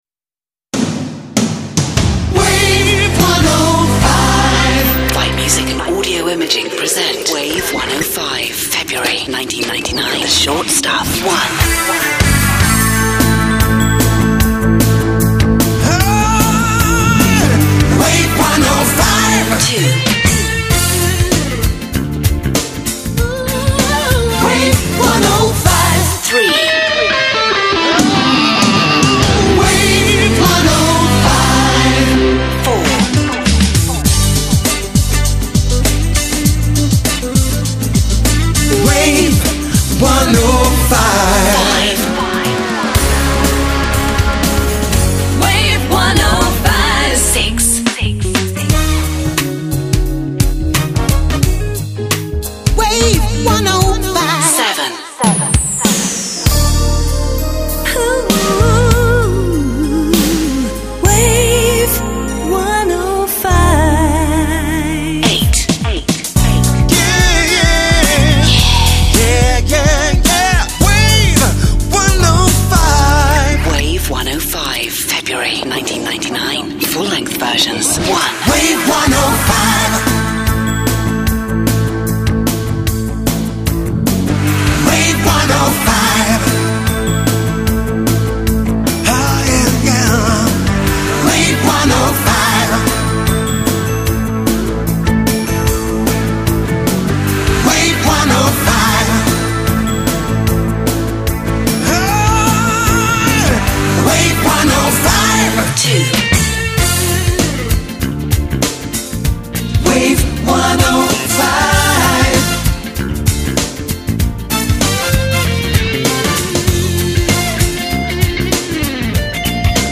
Their 2010 package contains six brand new jingles.